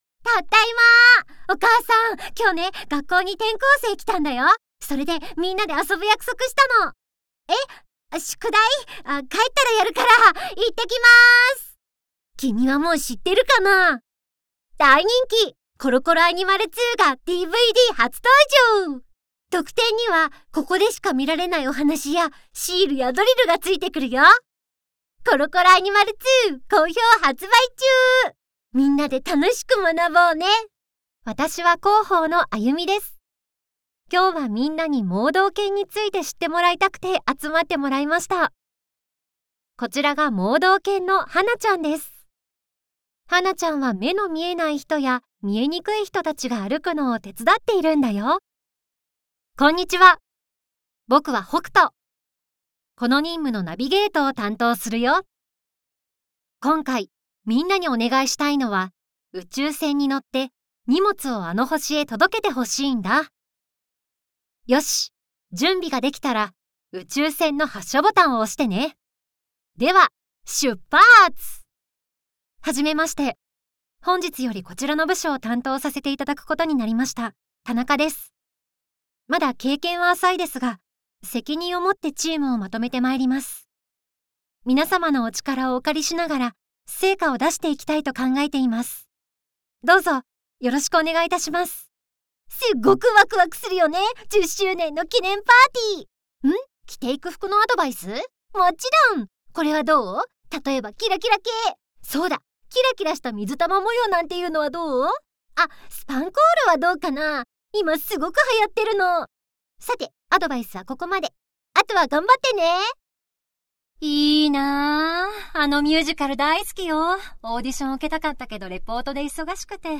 • 【キャラクター】 ①女の子②男の子③お姉さん④男の子２⑤社員⑥ポジティブキャラ⑦女子大生⑧ゆるキャラ